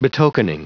Prononciation du mot betokening en anglais (fichier audio)
Prononciation du mot : betokening